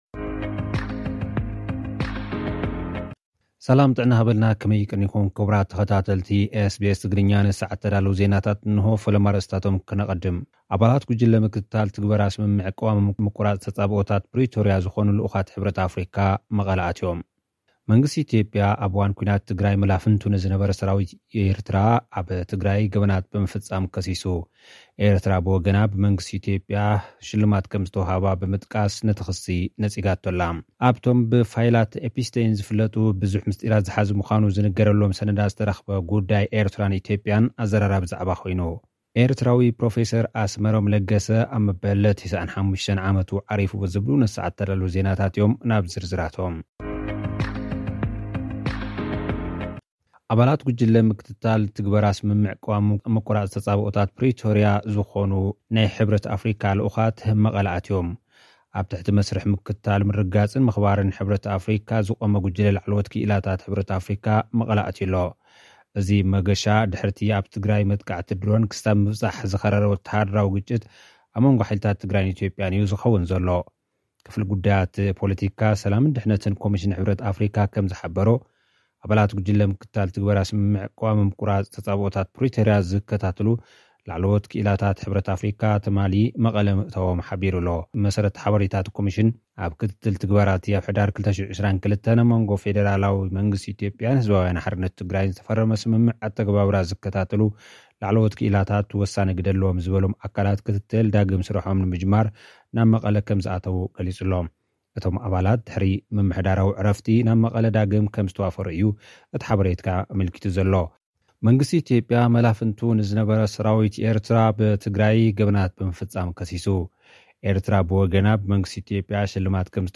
ኣባላት ጉጅለ ምክትታል ትግበራ ስምምዕነት ቀዋሚ ምቁራፅ ተፃብኦታት ፕሪቶርያ ዝኾነ ልኡኻት ሕብረት ኣፍሪቃ መቐለ ኣትዮም ። (ጸብጻብ)